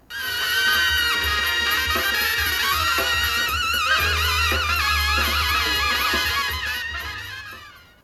実際の騒音で比較
音楽
音楽エアトースなし.mp3